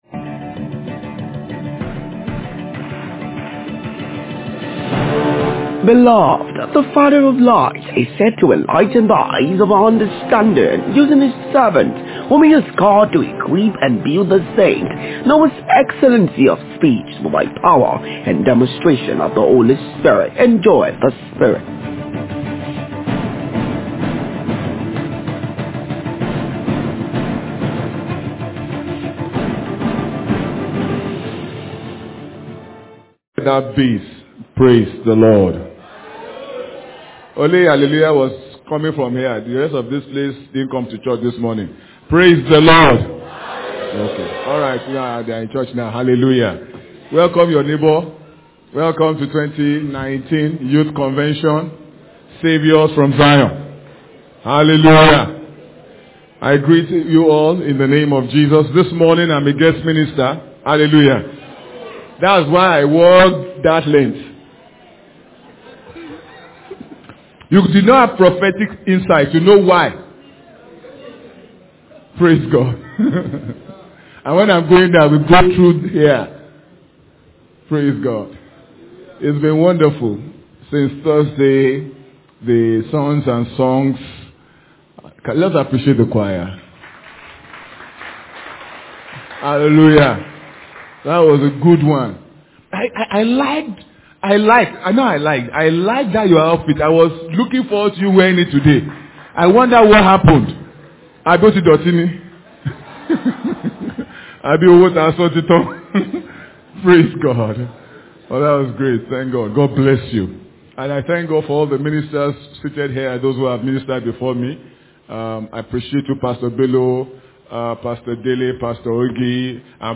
Youth Convention’19 Saviours from Zion Sunday Service – Power & Glory Tabernacle